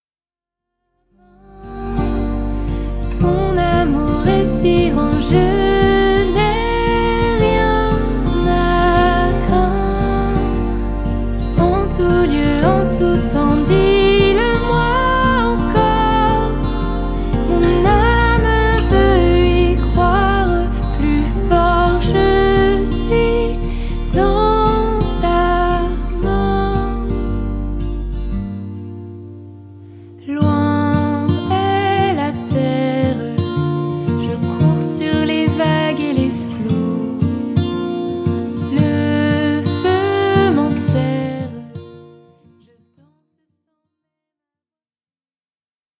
avec des mélodies apaisantes et une louange réconfortante.
ce sont 4 titres frais, doux et joyeux